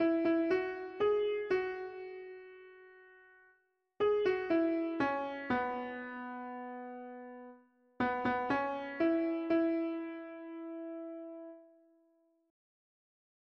traditional Ojibwe lullaby